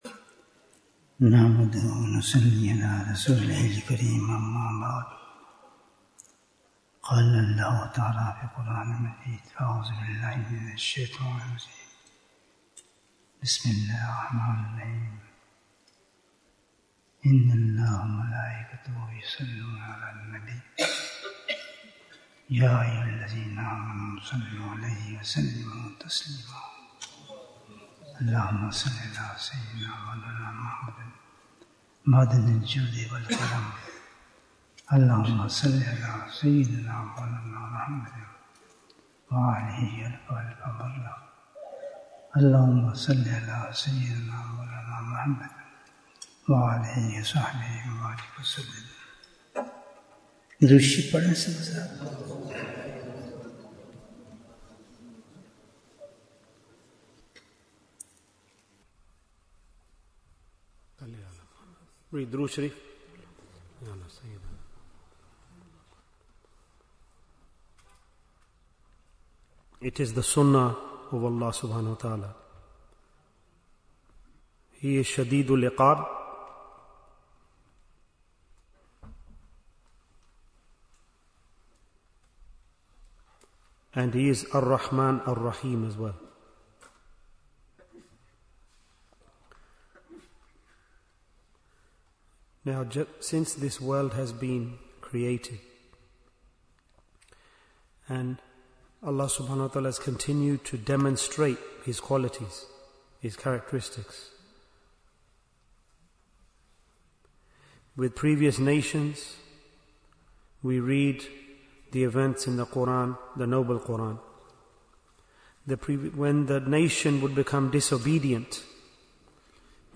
Bayan Annual Ijtema 2025